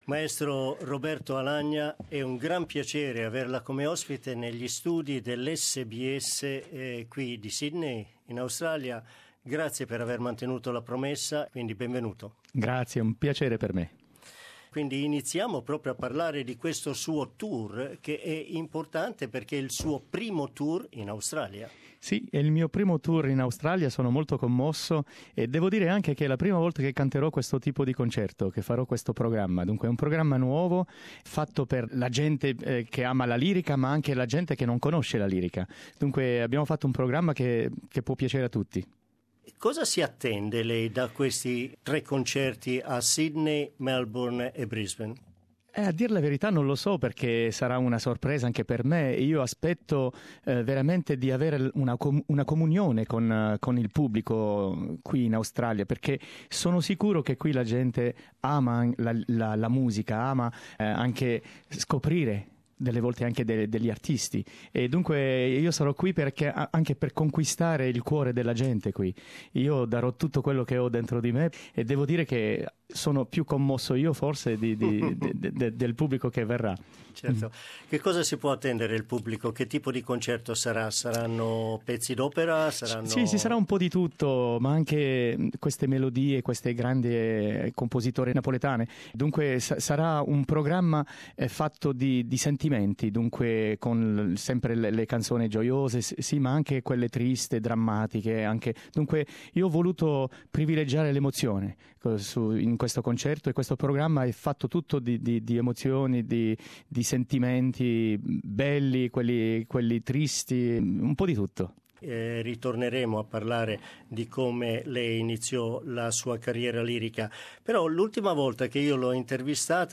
In questa intervista il tenore Roberto Alagna parla dei momenti più belli e di quelli più difficili della sua vita.